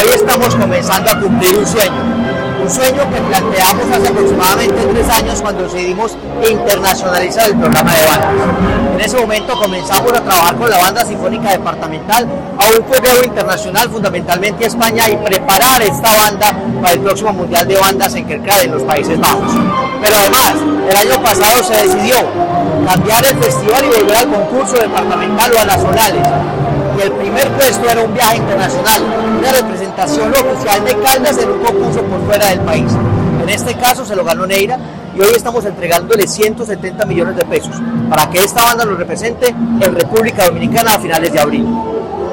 Diputado Hernán Alberto Bedoya.
Presidente-de-la-Asamblea-de-Caldas-Hernan-Alberto-Bedoya-recursos-bandas.mp3